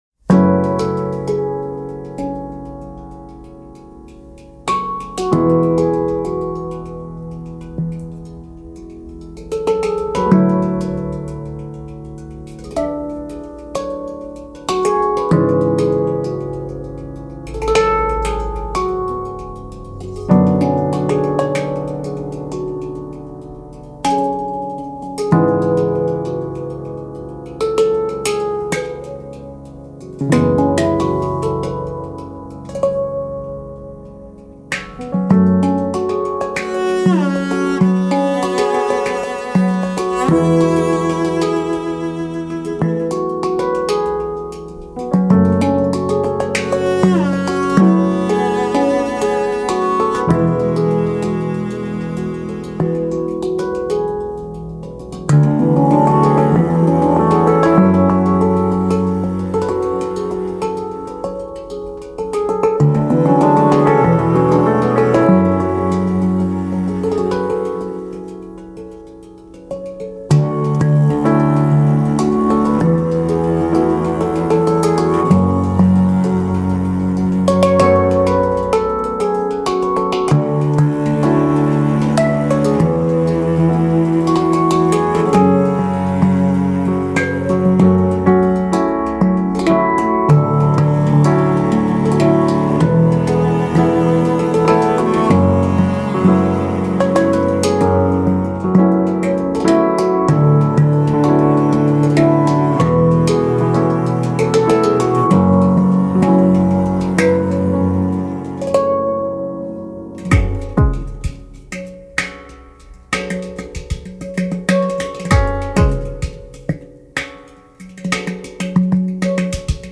piano
piano.mp3